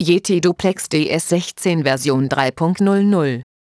ich habe Euch passend zur neuen Software 3.00 eine neue Start-Ansage für die DC/DS 16 aufgenommen.
Da es sich hier um Files handelt die mein Mac innerhalb des Betriebssystems erzeugt stellt sich wohl auch nicht die Frage nach dem Urheberrecht .